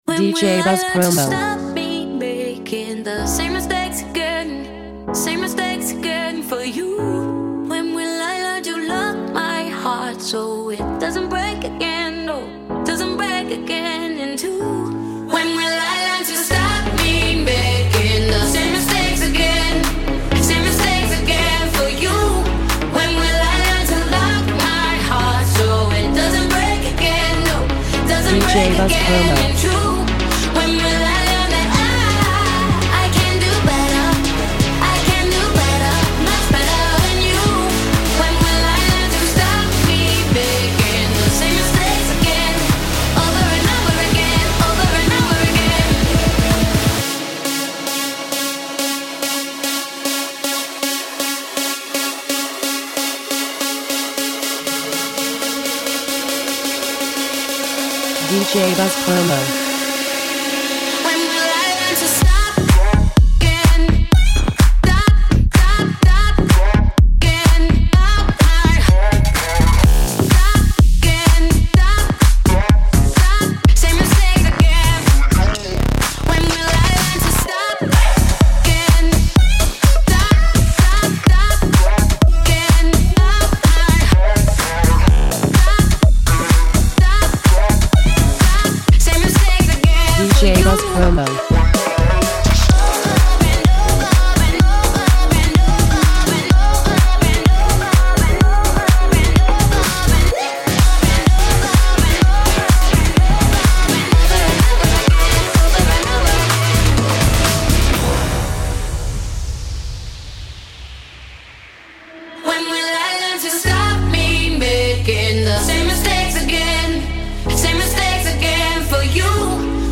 Club Mix
Catchy and upbeat
punchy electro-pop with huge danceability
megawatt vibrant vocals